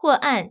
ivr-or_press.wav